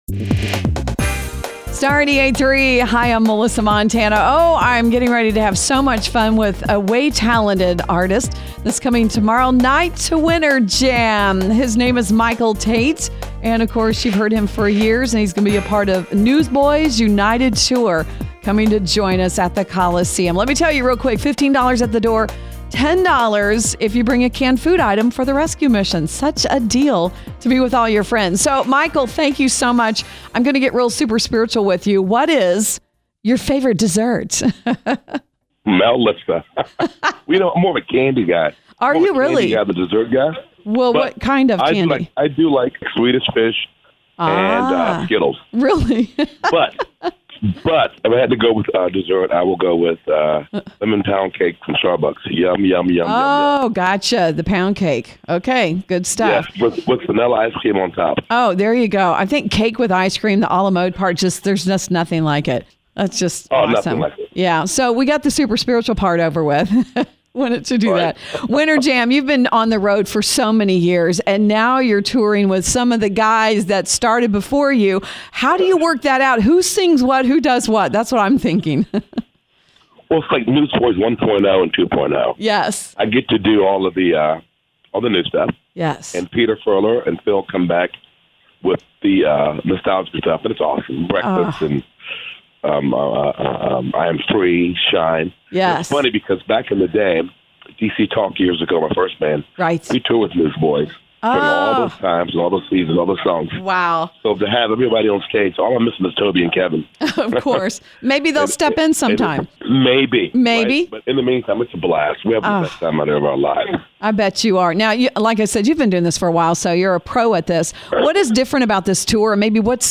Michael Tait, lead singer of Newsboys UNITED